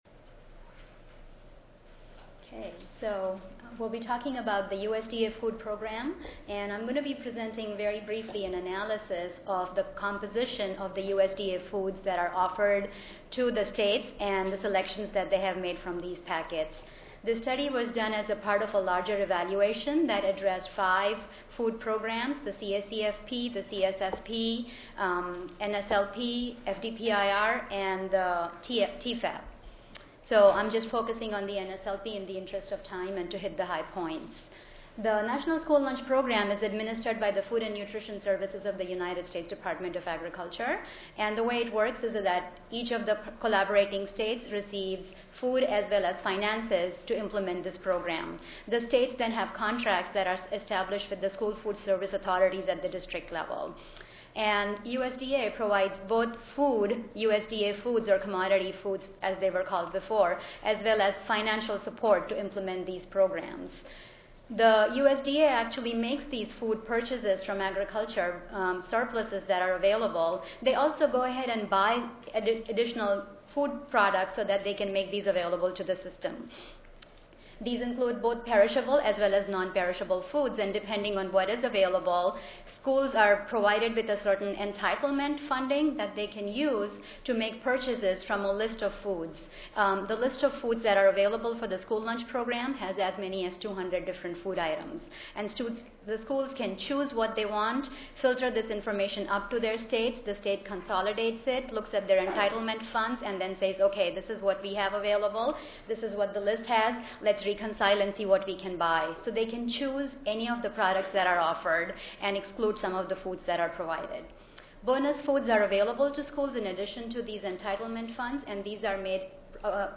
Oral The session will address effectiveness of Nutrition Education in SNAP, WIC, School Lunch/Breakfast, CACFP; SNAP restrictions; SNAP eligibility; incentives to purchase healthier options; emergency food assistance; etc.